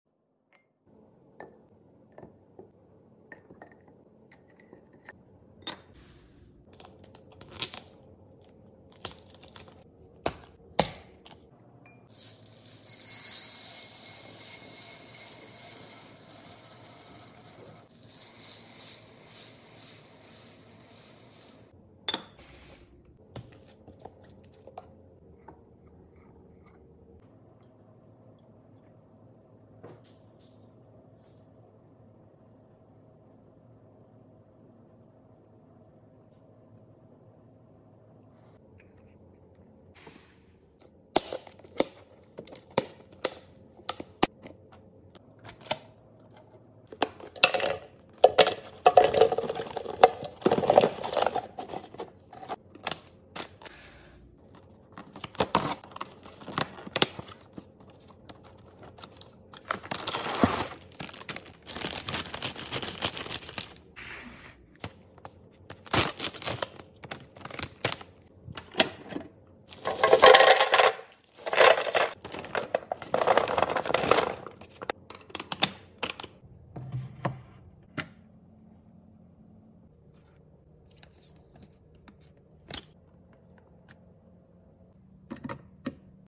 Some random house restocks to sound effects free download